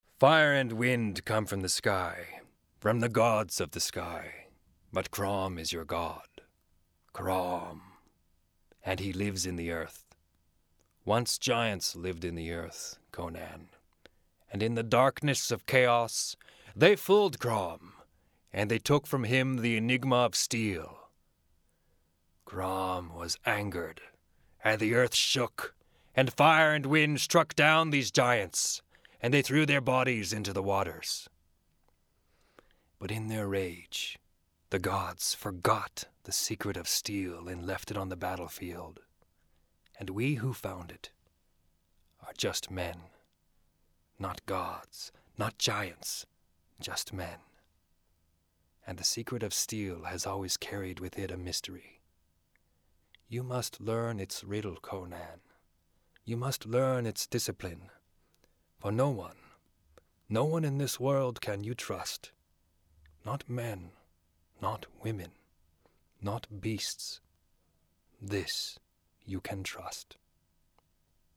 Voice Sample
英語・ナレーション
英語・キャラクター